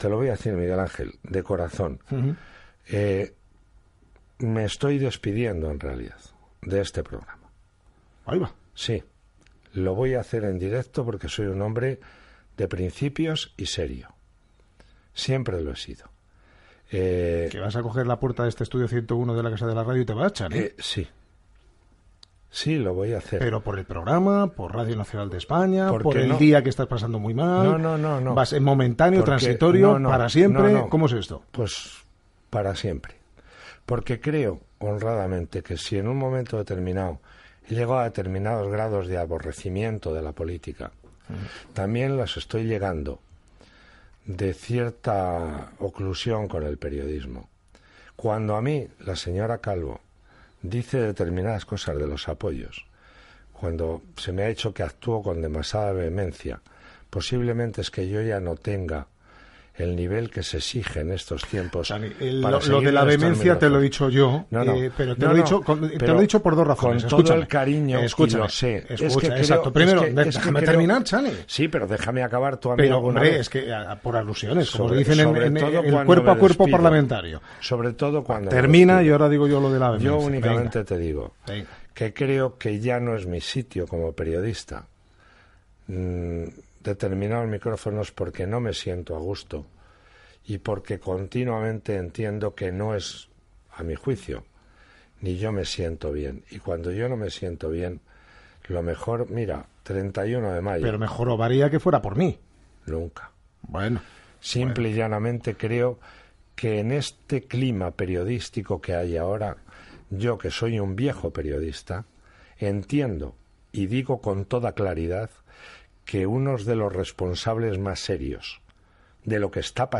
El periodista i col·laborador Chani Pérez Henares dimiteix en directe mentre s'emet l'informatiu i n'explica les causes.
Informatiu